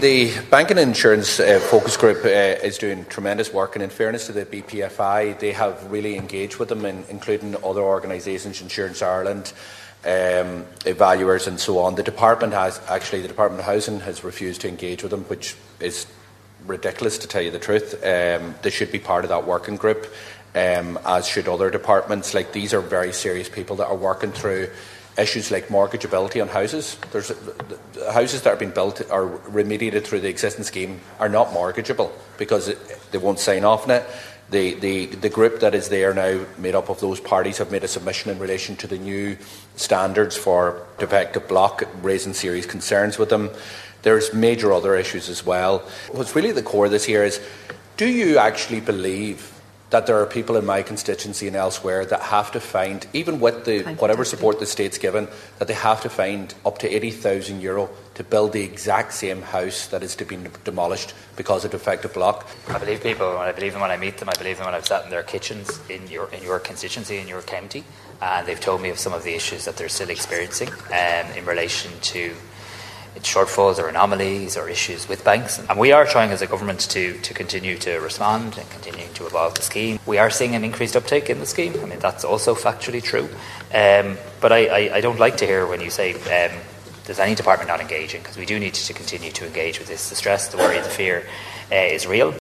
Mr Harris was responding to Donegal Deputy Pearse Doherty during Finance Minister’s questions shortly before the Dail broke up for Christmas last night.